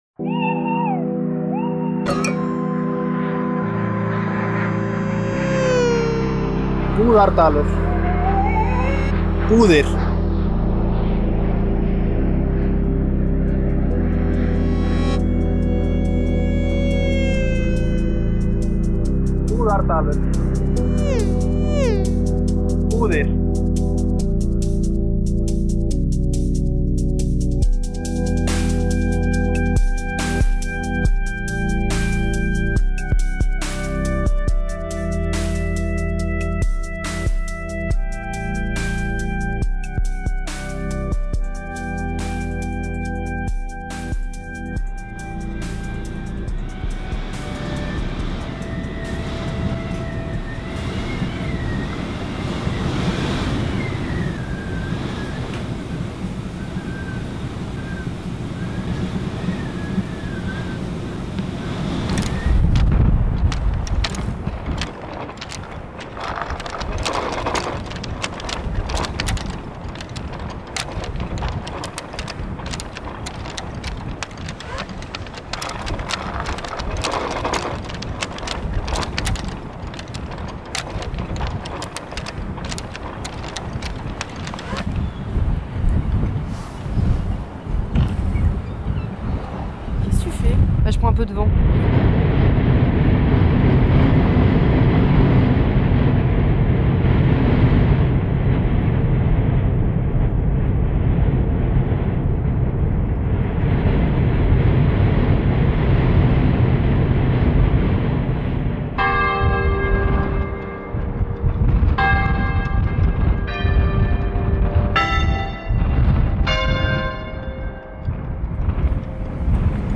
Le vent fait tanguer les bateaux sur la péninsule.